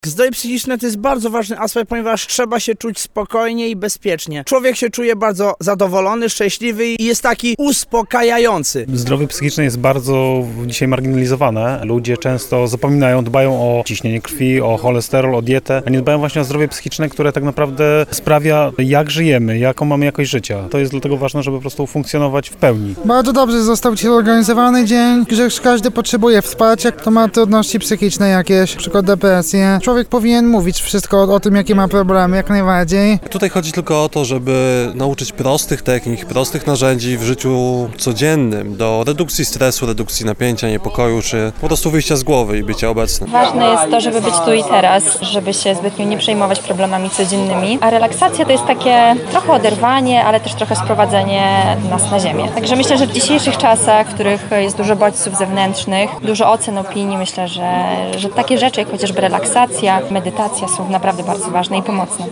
11-sonda-my-dla-innych.mp3